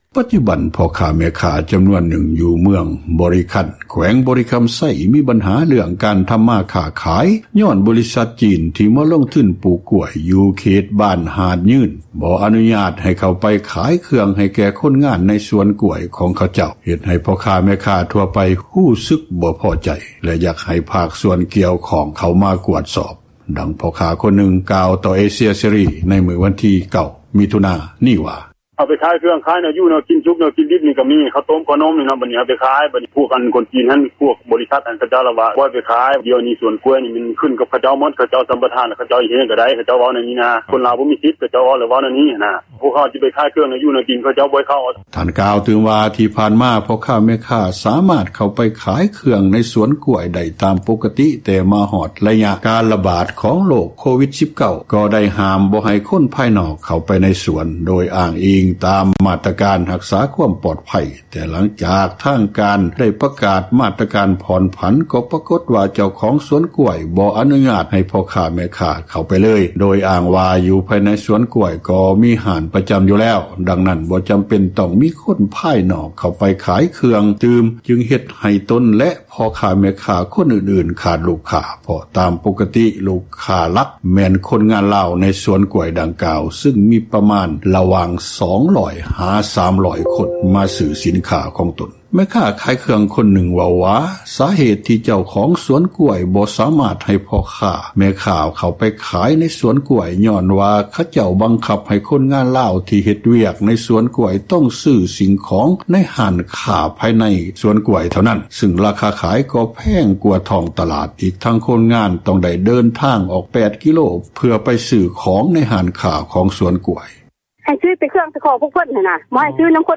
ປັດຈຸບັນ ພໍ່ຄ້າແມ່ຄ້າ ຄົນລາວ ຢູ່ເມືອງບໍຣິຄັນ ແຂວງບໍຣິຄຳໄຊ ບໍ່ສາມາດເຂົ້າໄປຂາຍເຄື່ອງ ຢູ່ໃນສວນກ້ວຍຈີິນ ຢູ່ເຂດບ້ານຫາດຍືນໄດ້ ຍ້ອນຖືກຫ້າມ ຈາກບໍຣິສັດ ວິເອັສ ຈຳກັດ ທີ່ຈີນເປັນຜູ້ລົງທຶນ ເຮັດໃຫ້ ພວກຂະເຈົ້າບໍ່ພໍໃຈ ຢາກໃຫ້ພາກສ່ວນກ່້ຽວຂ້ອງ ເຂົ້າມາແກ້ໄຂ, ດັ່ງພໍ່່ຄ້າຄົນນຶ່ງ ກ່າວຕໍ່ເອເຊັຽເສຣີ ໃນມື້ວັນທີ 9 ມິຖຸນາ 2020 ນີ້ວ່າ: